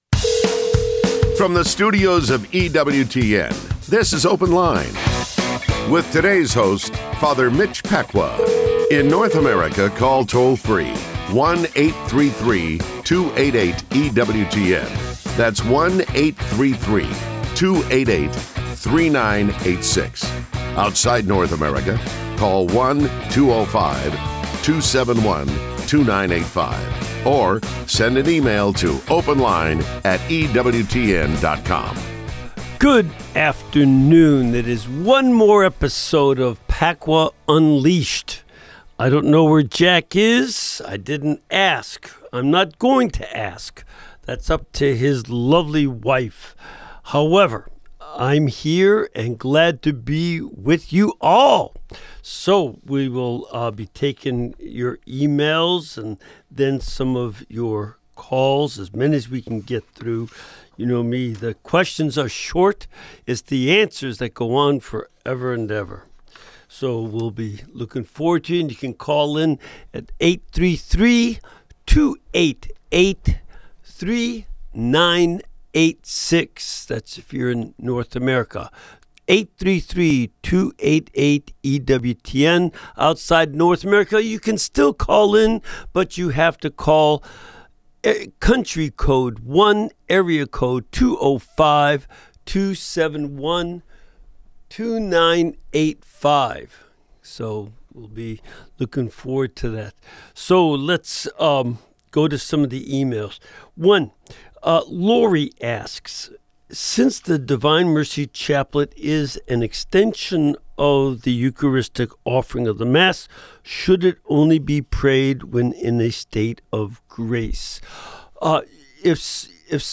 Call in: 833-288-EWTN (3986) | Bible